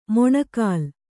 ♪ moṇa kāl